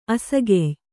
♪ asagey